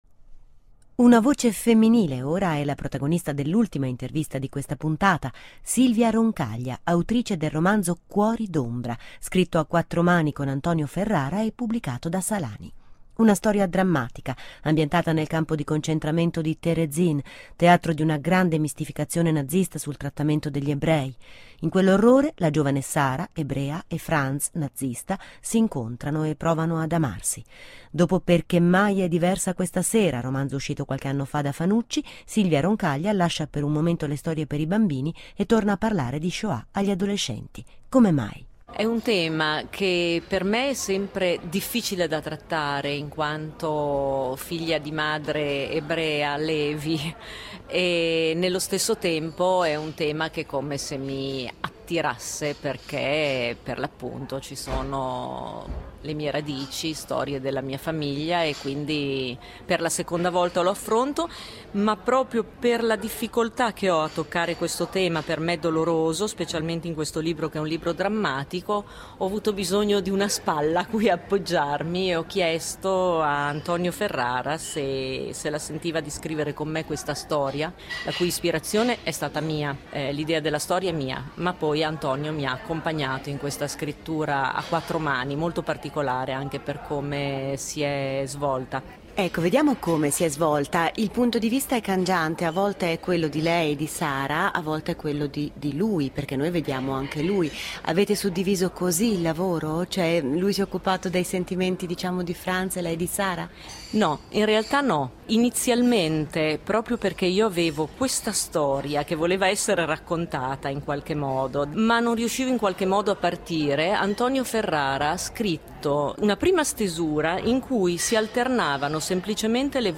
Intervista sul libro “Cuori d’ombra” da  Geronimo • Bologna 2015